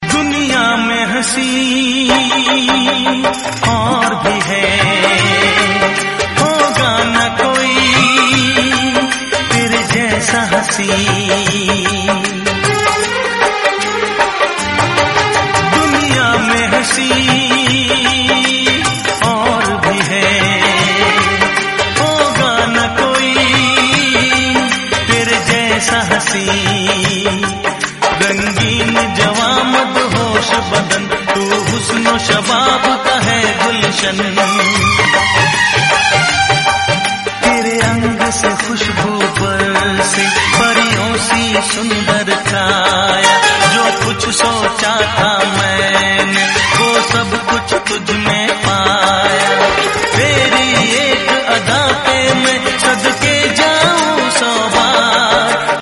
Enjoy the romantic vibe every time your phone rings.